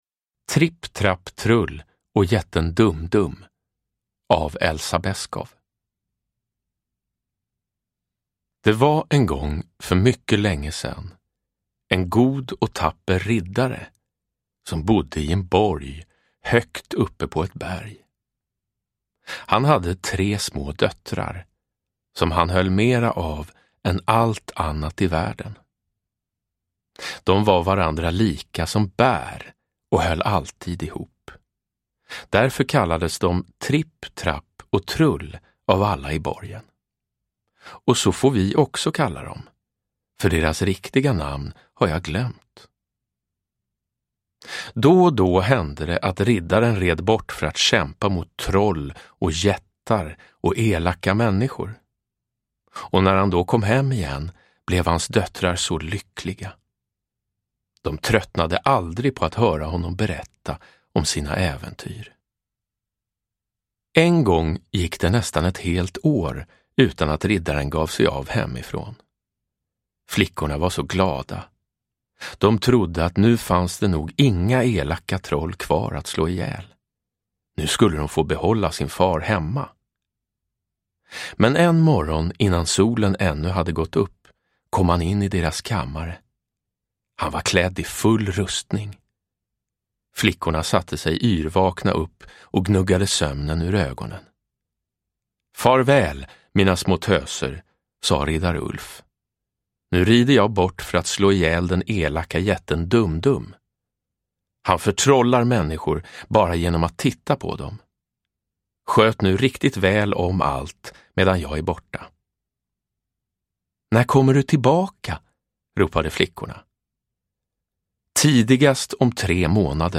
Uppläsare: Jonas Karlsson
Ljudbok